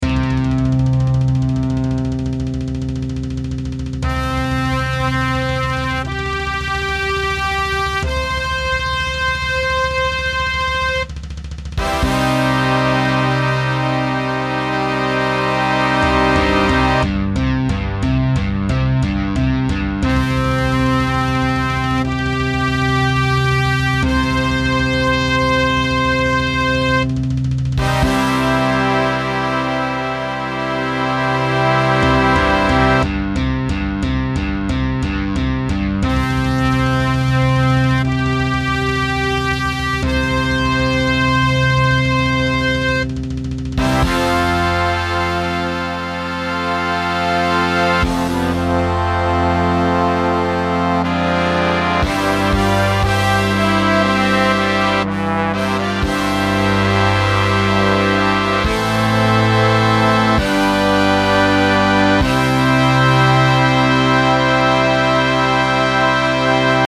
scored for full big band